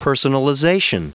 Prononciation du mot personalization en anglais (fichier audio)